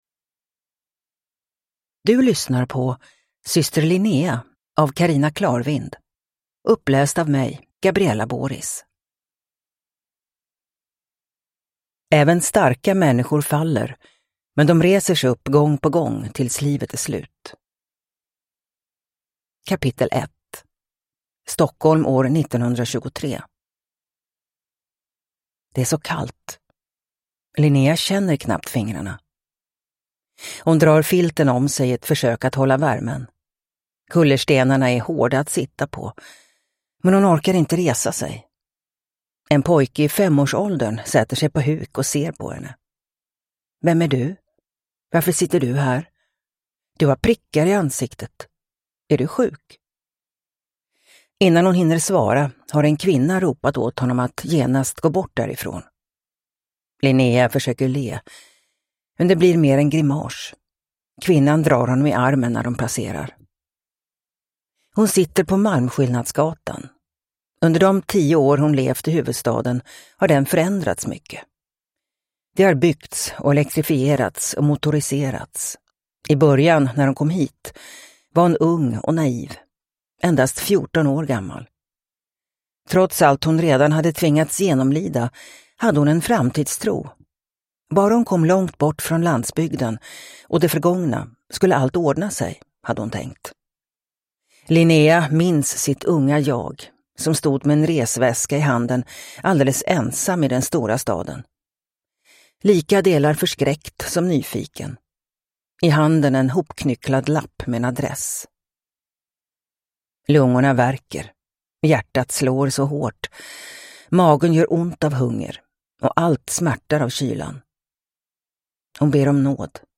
Syster Linnea (ljudbok) av Carina Clarvind | Bokon